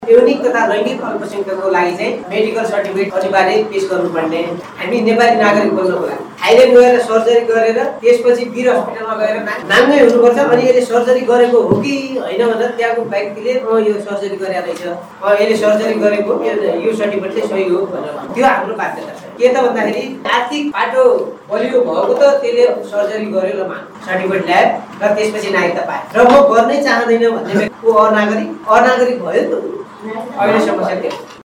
निलहिरा समाजले सुर्खेतको वीरेन्द्रनगरमा आयोजना गरेको अन्तरक्रिया कार्यक्रममा सहभागि भएका उनिहरुले यस्तो बताएका हुन् ।